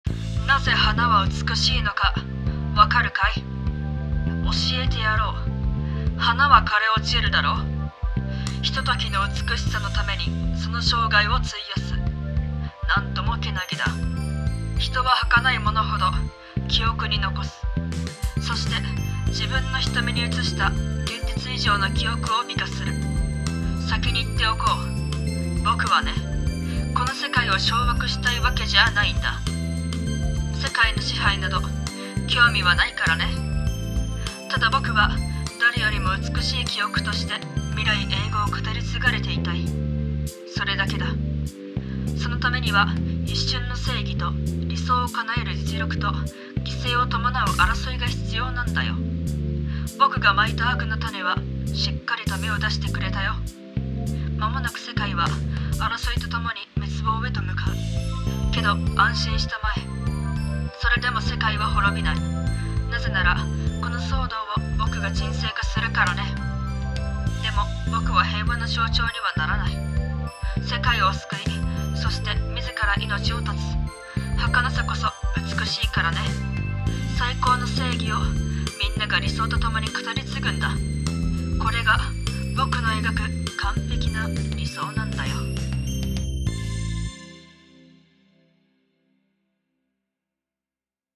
声劇【完璧な理想】※悪役声劇